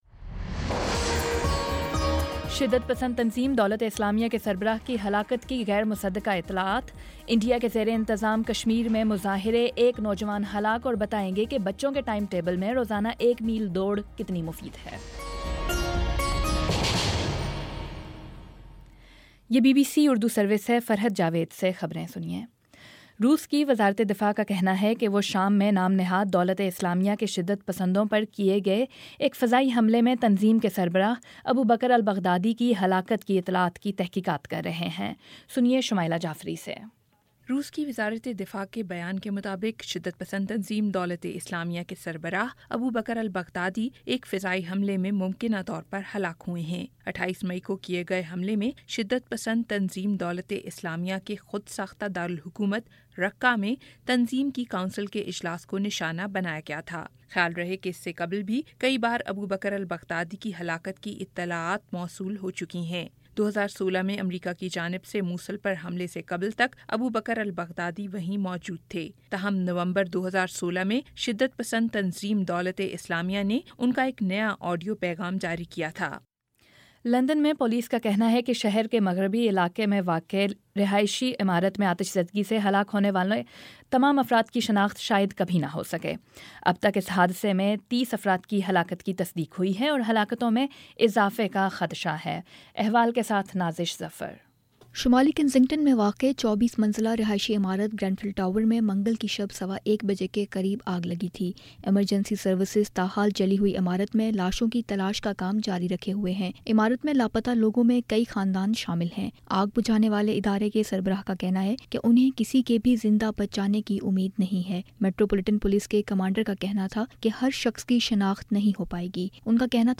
جون 16 : شام پانچ بجے کا نیوز بُلیٹن